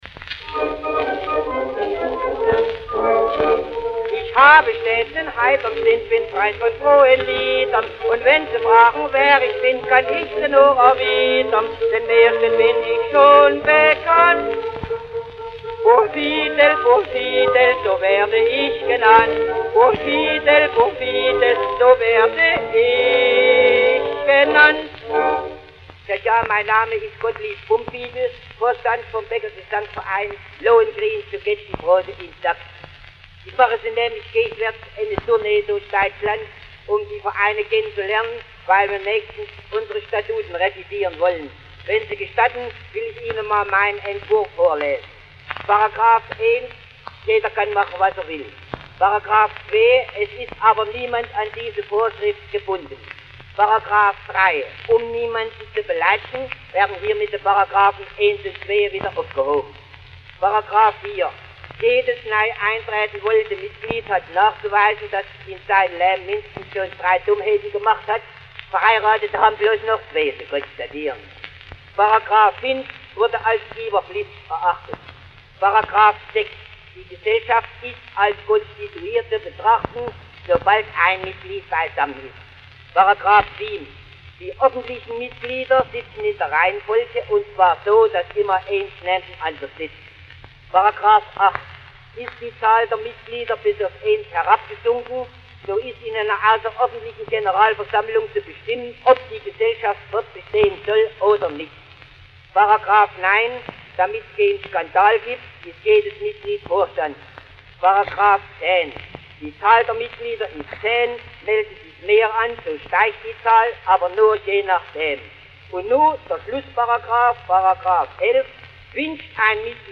Schellackplattensammlung Schw�bisches Kulturarchiv